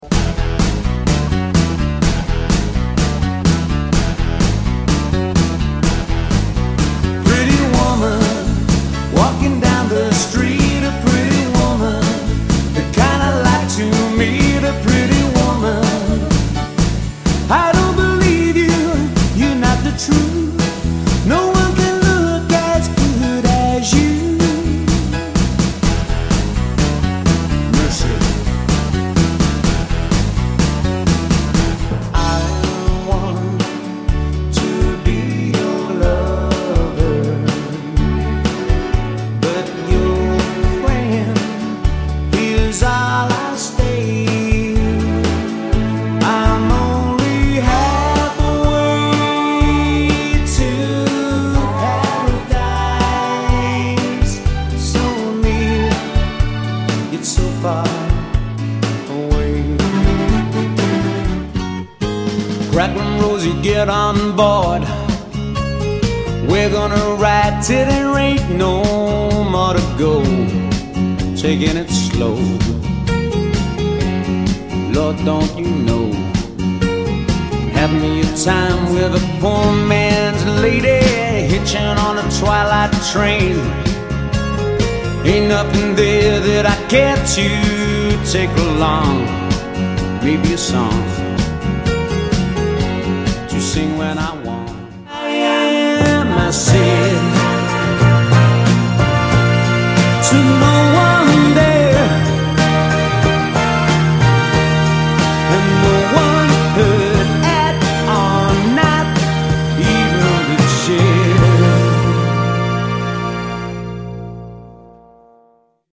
Super songs from background dinner music to disco classics!